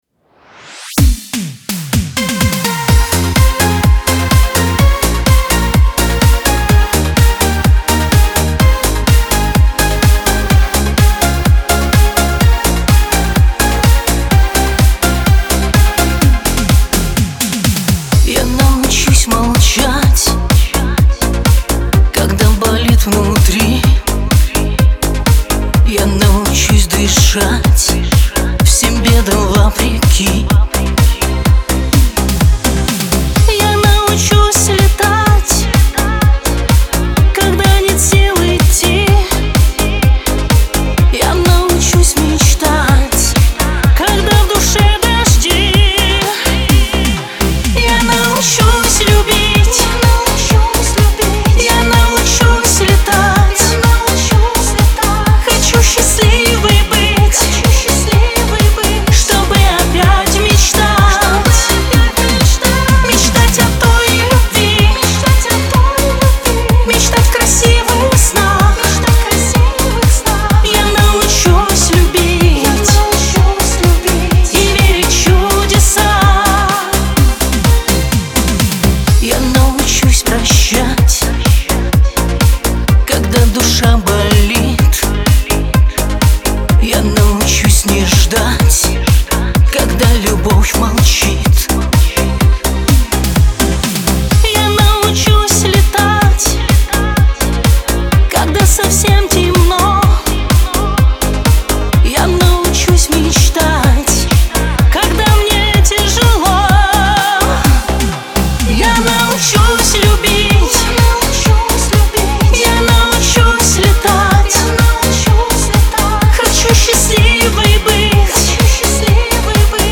диско
эстрада
pop